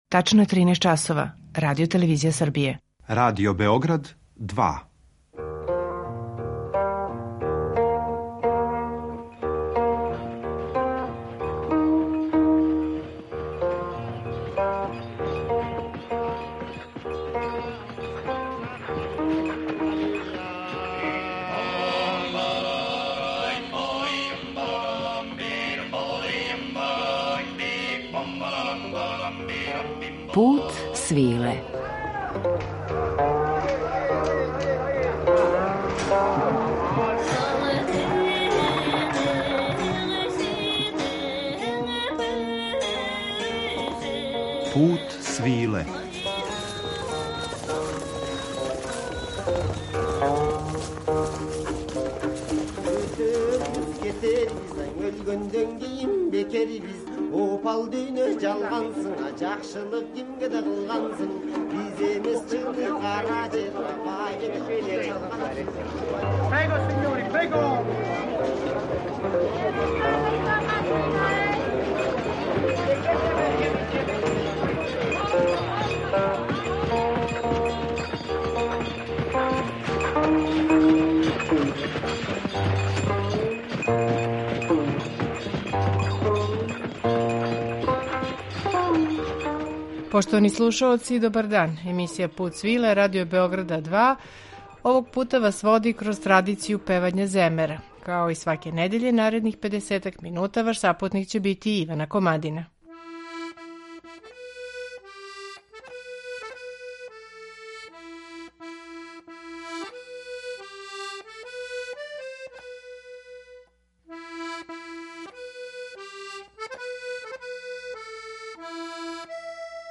Земер